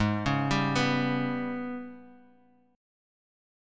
AbmM7bb5 chord